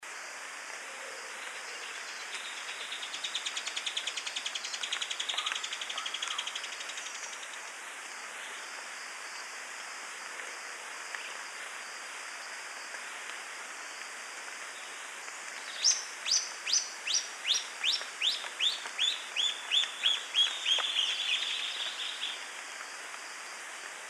7. Barn Swallow (Hirundo rustica)
Sound: A cheerful, twittering stream of notes.